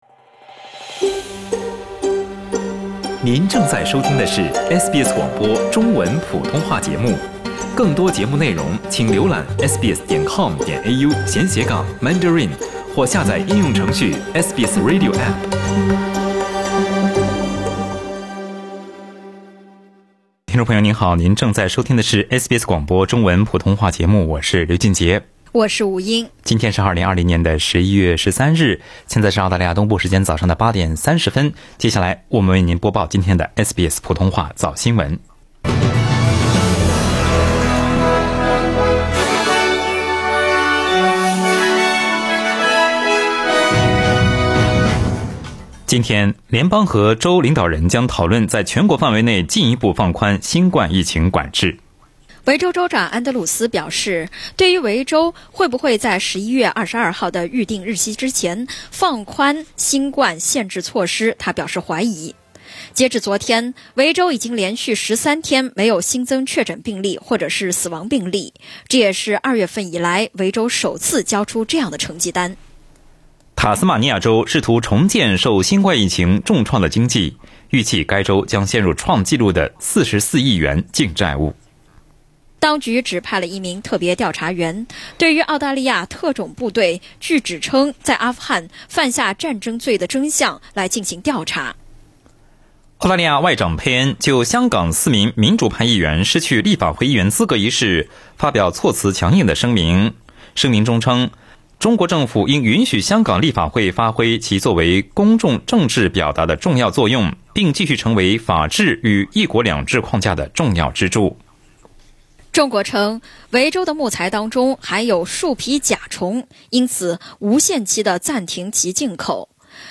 SBS早新闻（11月13日）